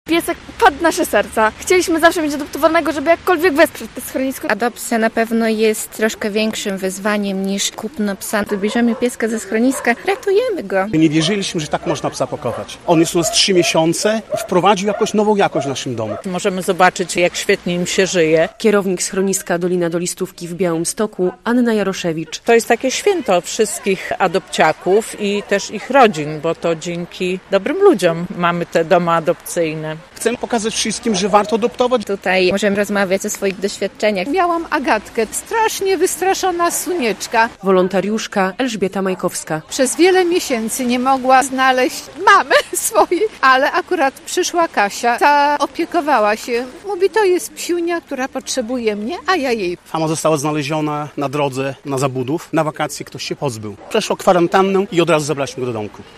W sobotę (18.10) w parku Planty odbyła się 9. edycja Parady Adopciaków.
Podczas parady możemy rozmawiać o swoich doświadczeniach - mówi uczestniczka parady.